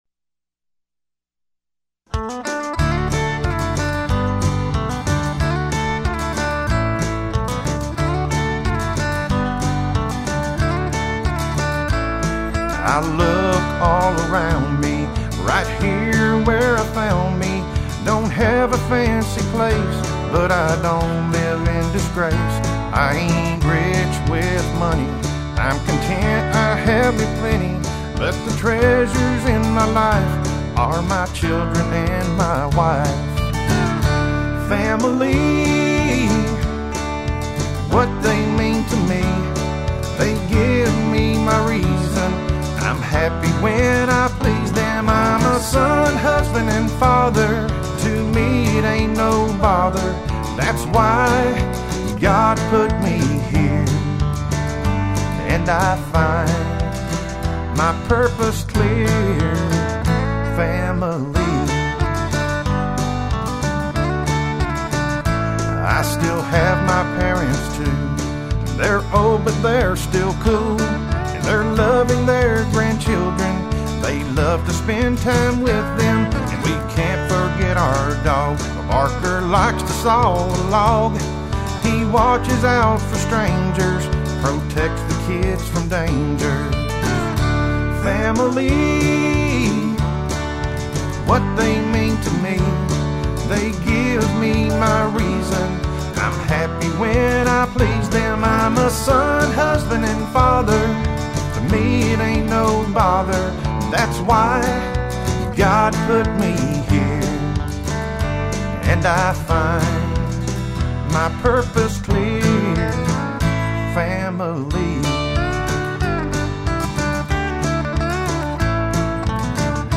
Complete Demo Song, with lyrics and music: